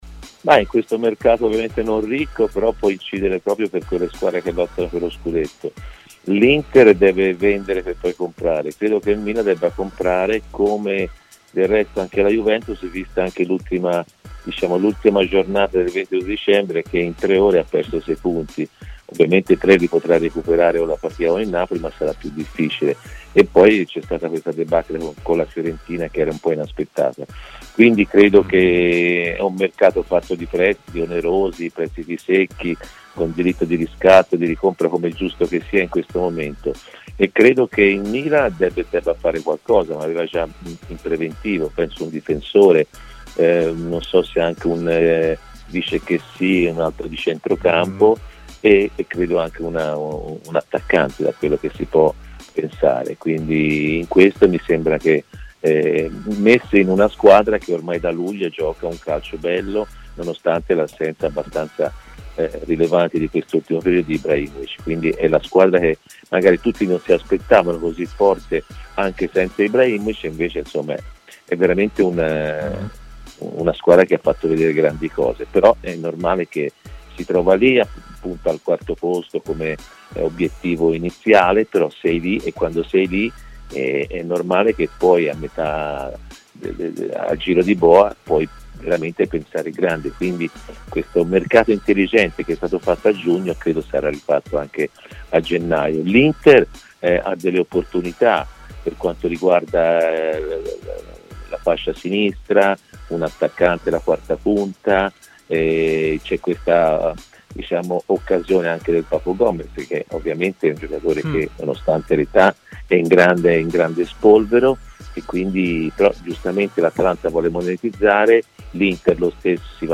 Antonio Di Gennaro, ex allenatore del Milan, ha parlato ai microfoni di TMW Radio: "Il mercato, pur non essendo ricco, può incidere per le squadre che sono in corsa per lo Scudetto.